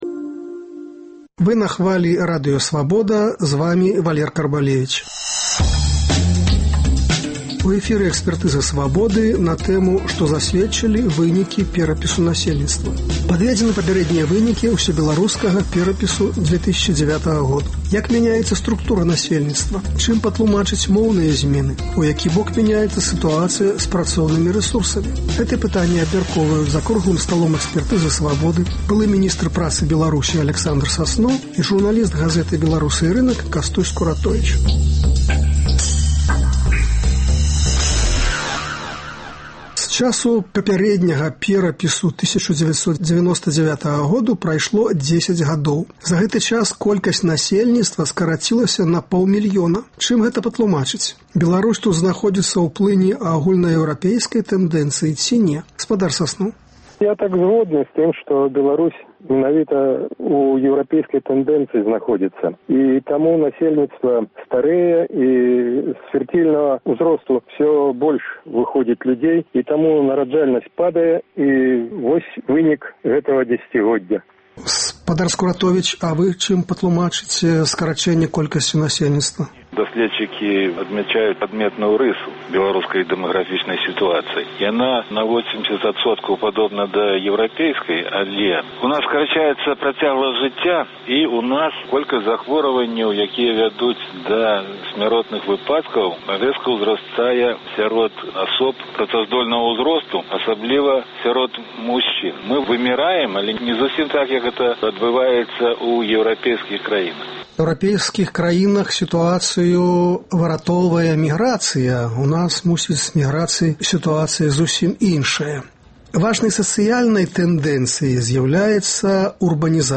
Чым патлумачыць моўныя зьмены? У які бок мяняецца сытуацыя з працоўнымі рэсурсамі? Гэтыя пытаньні абмяркоўваюць за круглым сталом